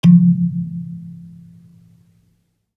kalimba_bass-F2-pp.wav